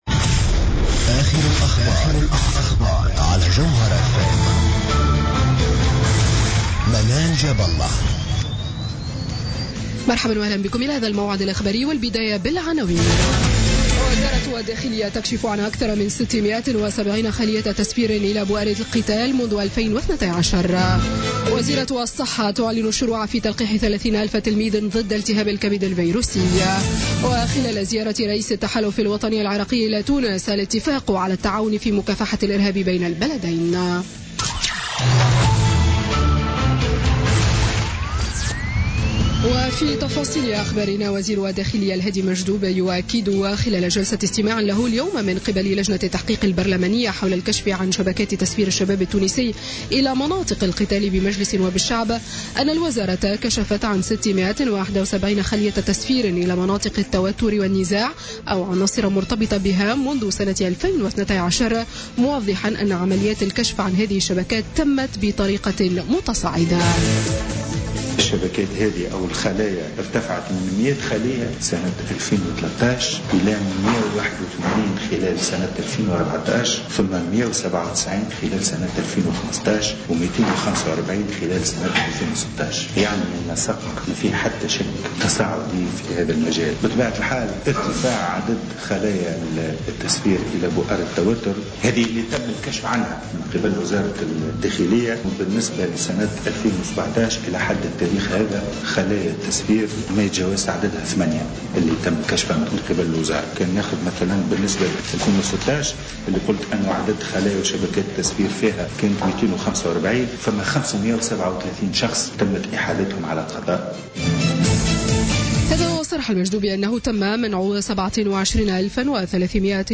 نشرة أخبار السابعة مساء ليوم الجمعة 21 أفريل 2017